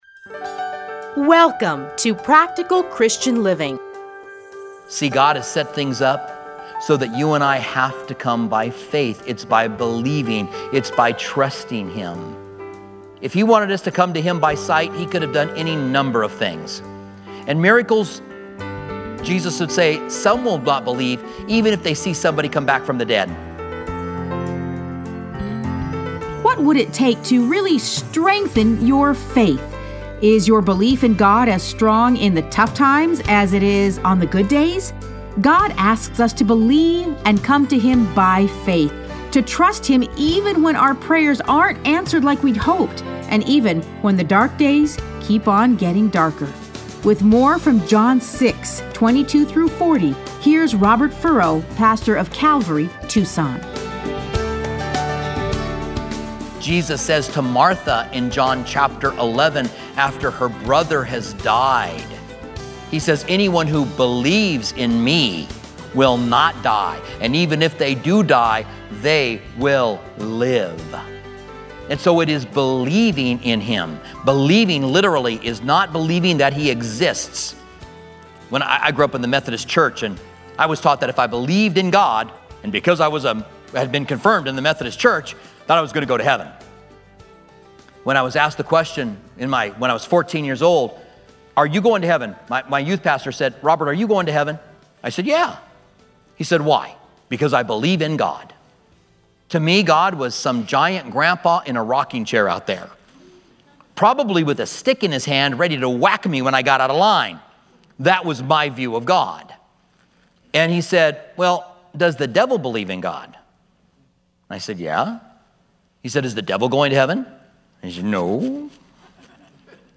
Listen to a teaching from John 6:22-40.